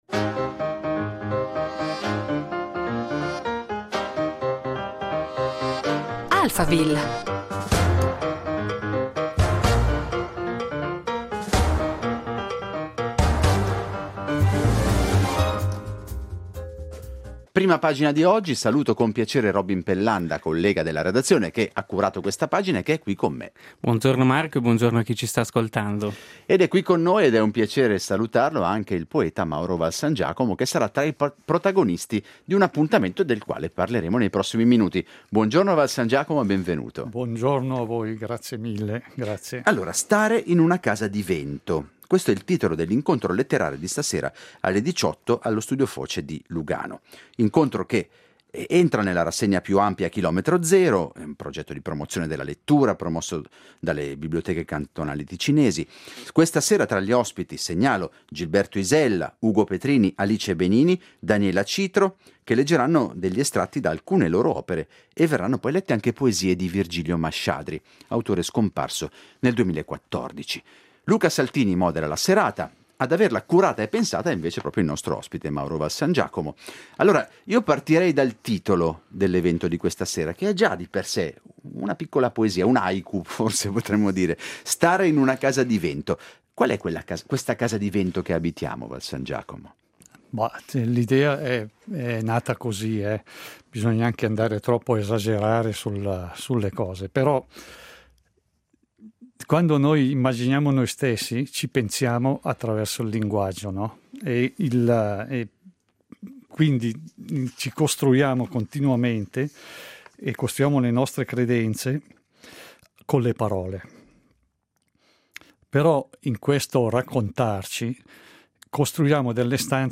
Incontro letterario con letture di poesie